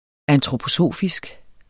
Udtale [ antʁopoˈsoˀfisg ]